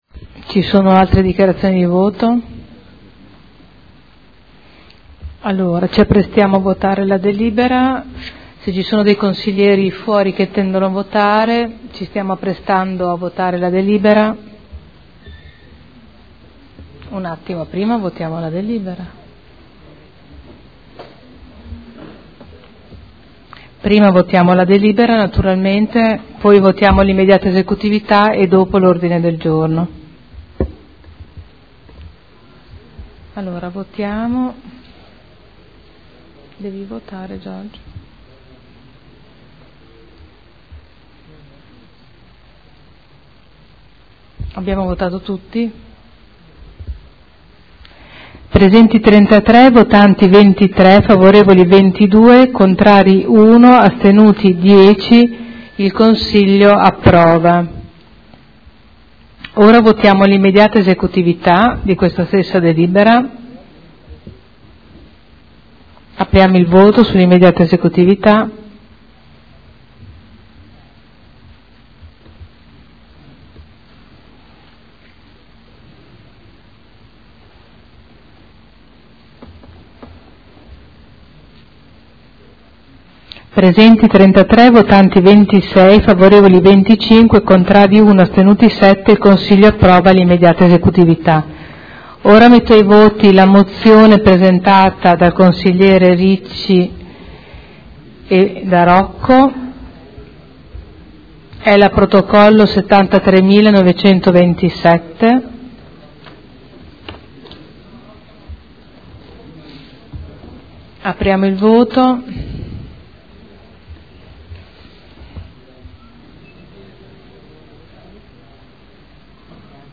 Presidente